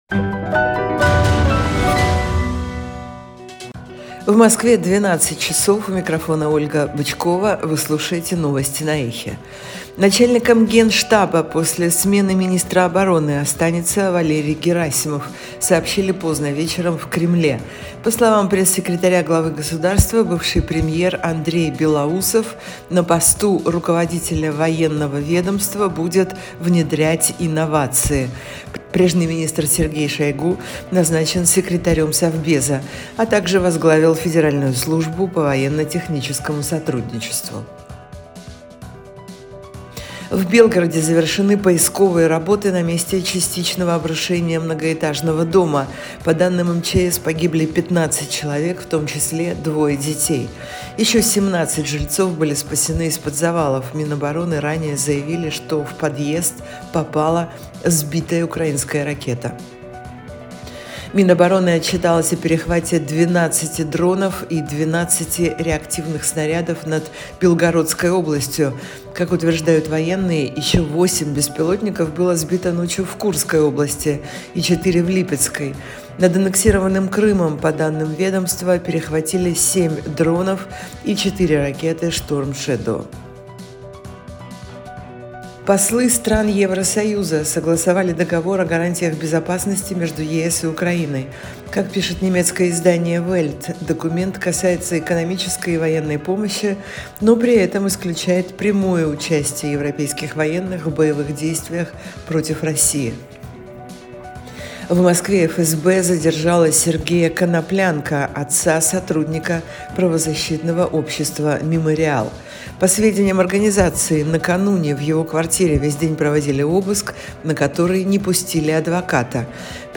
Слушайте свежий выпуск новостей на «Эхе»
Новости